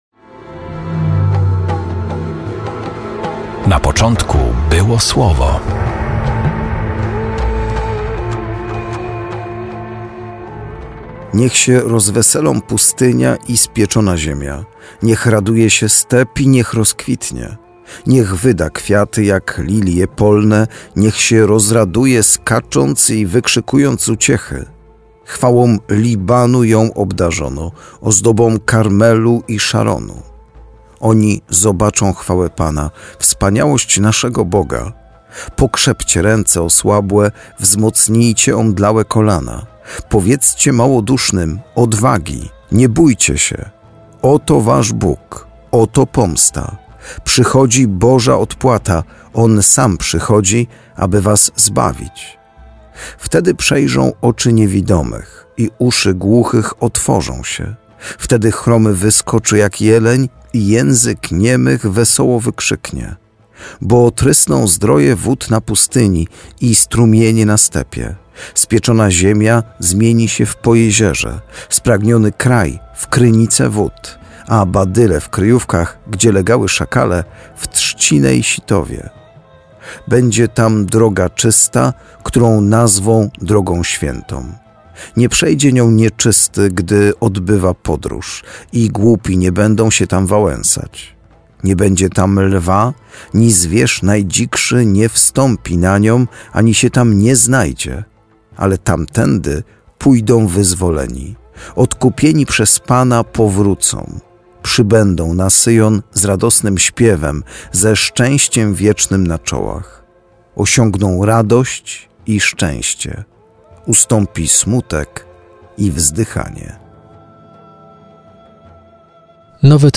Perły ukryte w liturgii słowa odkrywają księża: